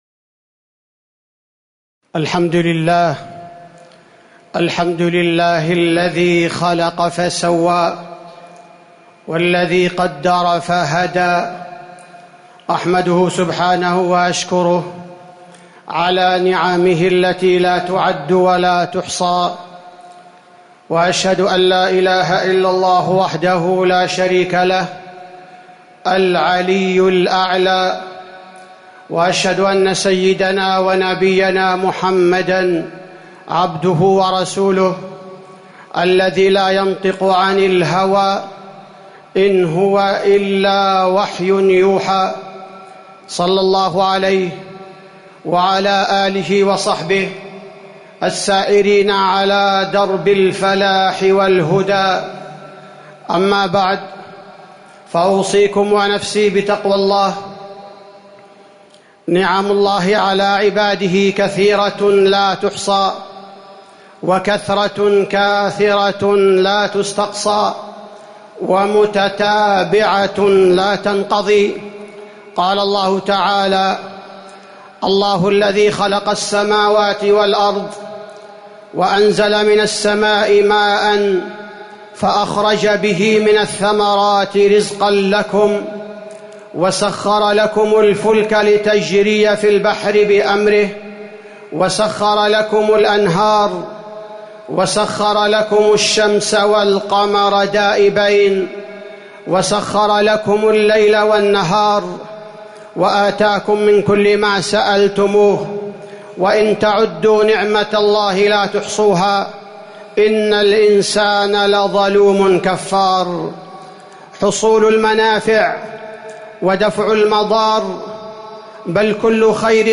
تاريخ النشر ٢٥ شوال ١٤٤٠ هـ المكان: المسجد النبوي الشيخ: فضيلة الشيخ عبدالباري الثبيتي فضيلة الشيخ عبدالباري الثبيتي وما بكم من نعمة فمن الله